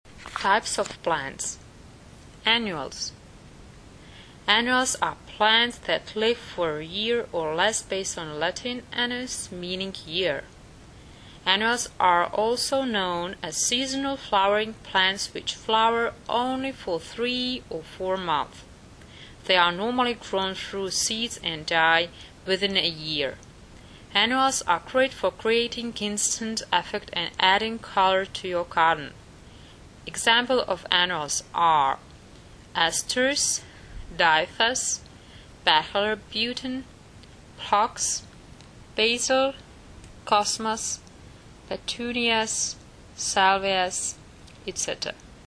Nahrávka výslovnosti (*.MP3 soubor): 4